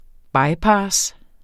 bypass substantiv, fælleskøn Bøjning -en, -er eller -, -erne Udtale [ ˈbɑjˌpɑːs ] Oprindelse kendt fra 1990 fra engelsk bypass af pass by 'gå forbi, omgå' Betydninger 1.